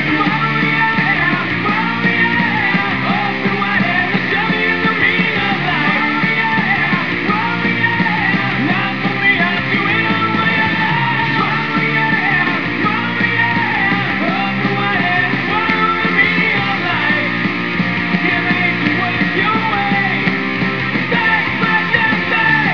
Unfortunately, the clips are not of best quality.